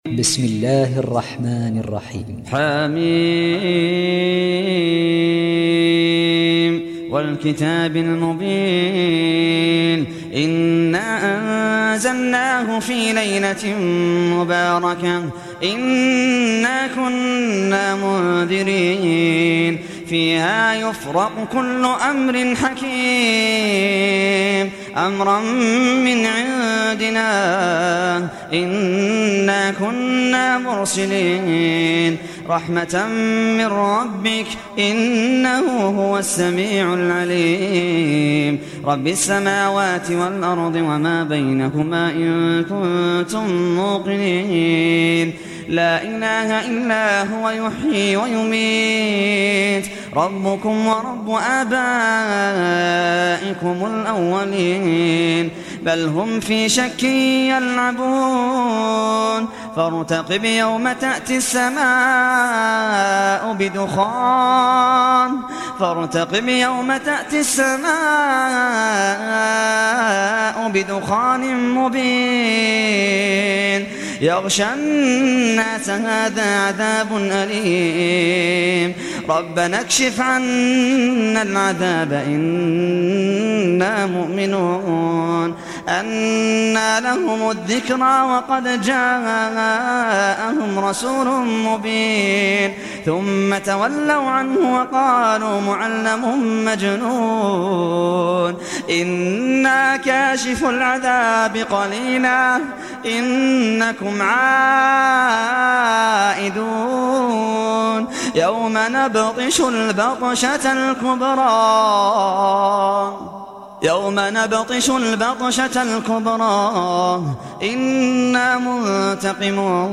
القارئ محمد اللحيدان